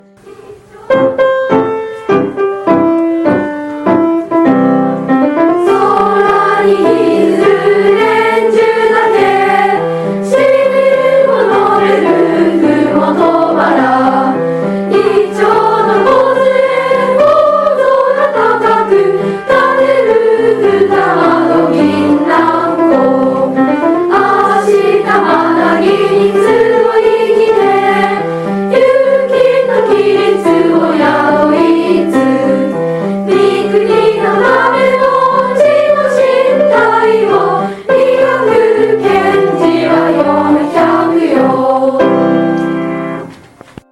現行政区  旧行政区 No 小学校名  校歌楽譜・歌詞・概要  校歌音源（歌・伴奏） 　　備考